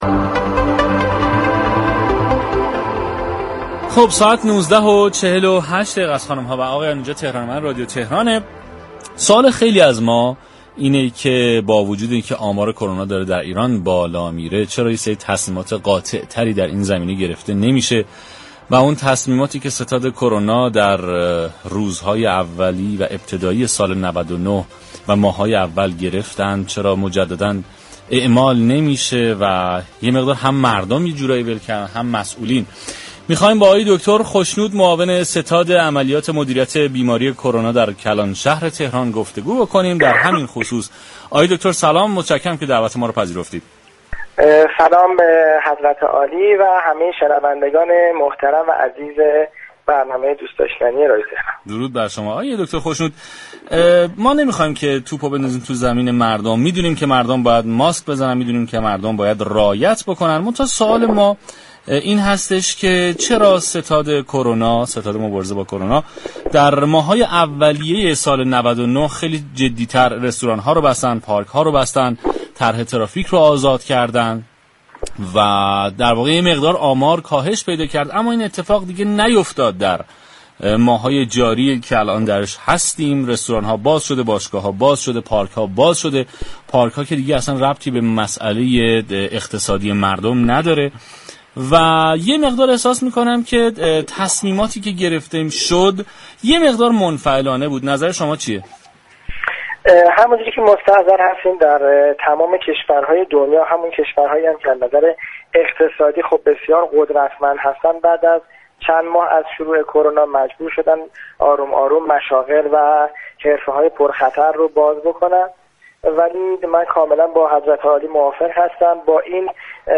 در گفتگو با تهران من